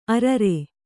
♪ arare